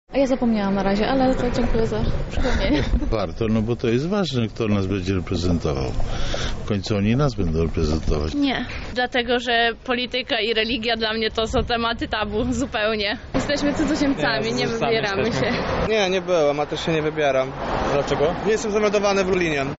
Obywatele niechętnie szli do urn, frekwencja w Lubelskiem była niższa niż średnia krajowa. Zapytaliśmy mieszkańców Lublina, czy chętnie głosują.